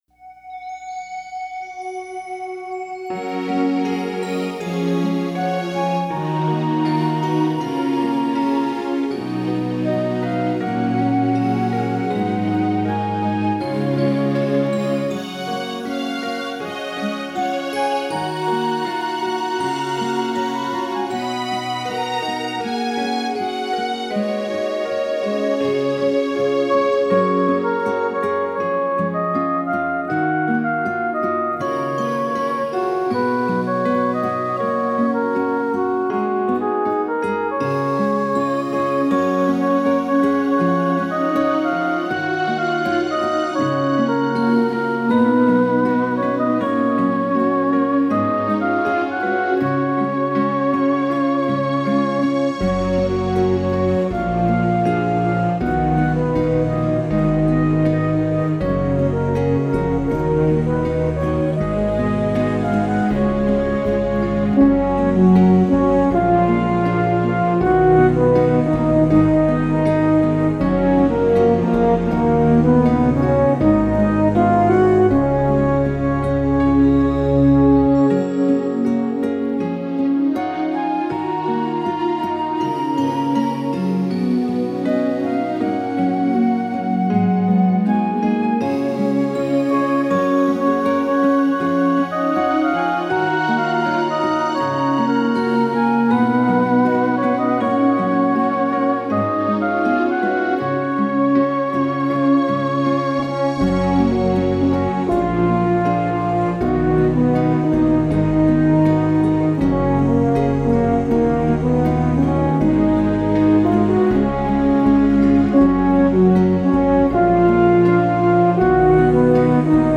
Initially, I worked with an arranger to create these lush arrangements.
3-so-real-instrumental.mp3